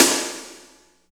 51.02 SNR.wav